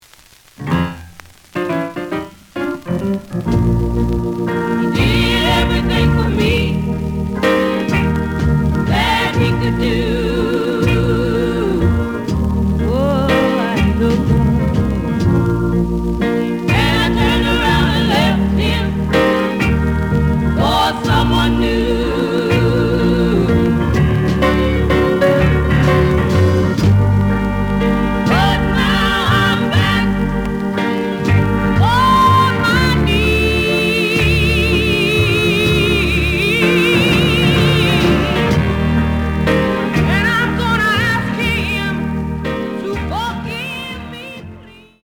The audio sample is recorded from the actual item.
●Genre: Rhythm And Blues / Rock 'n' Roll
Slight click noise on middle of B side due to a bubble.)